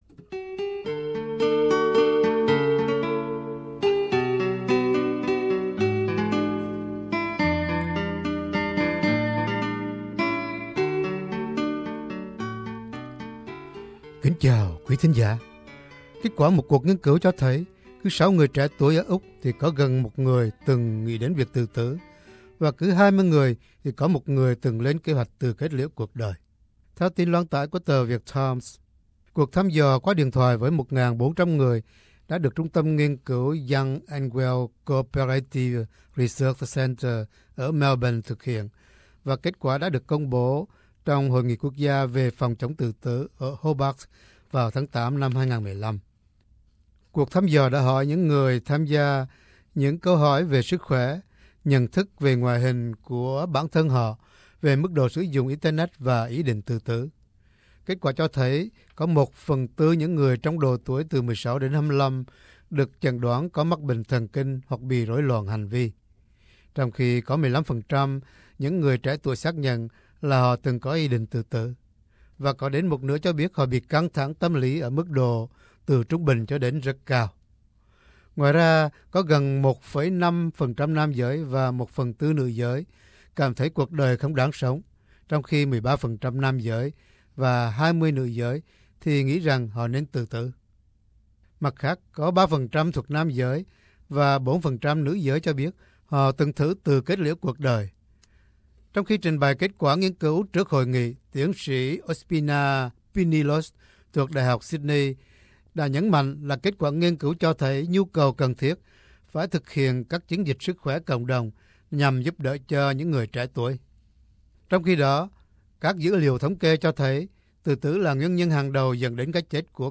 buổi trò chuyện